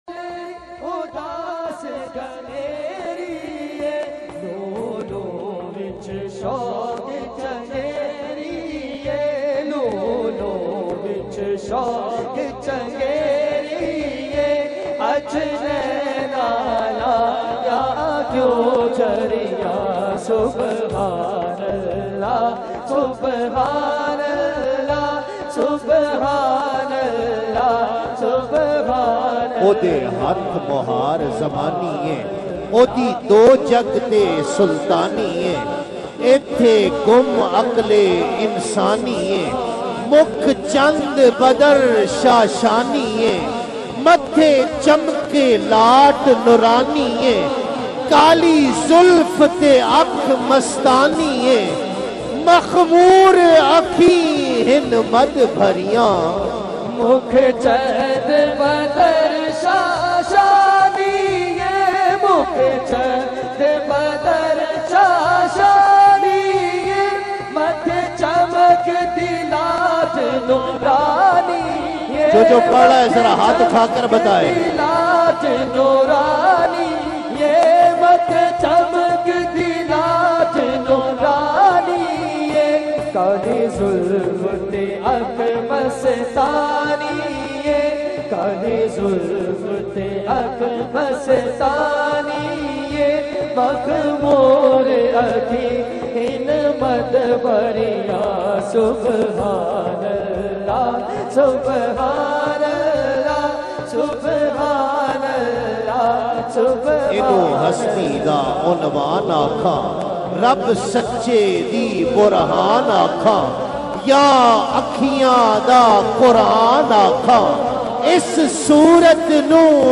PUNJABI NAAT
in a Heart-Touching Voice